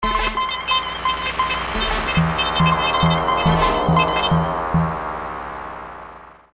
In questo boxxetto qua di fianco.. troveremo dei loopz fatti da me con un programmino spettacolare... che si chiama Fruity Loops versione 3.4